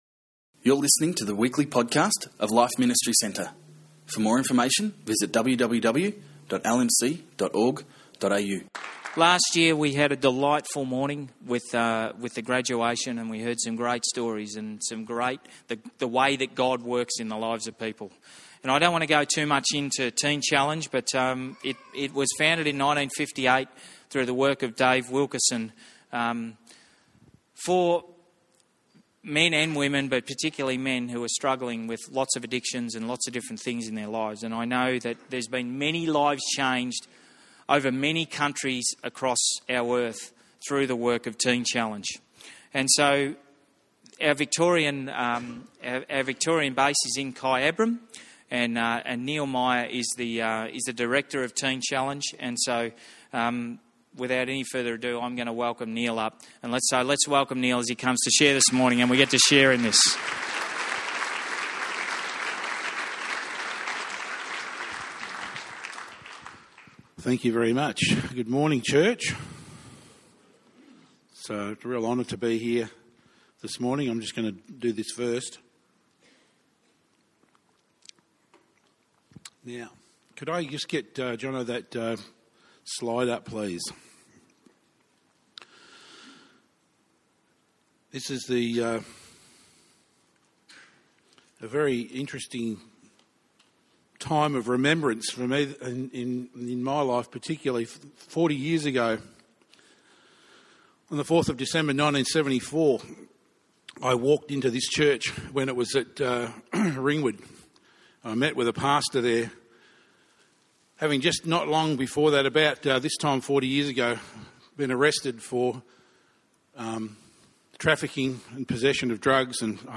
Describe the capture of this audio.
For the last 2 years, we've had the privilege of hosting their Graduation Ceremony at LMC. What a blessing to hear the amazing stories of restoration from each person involved.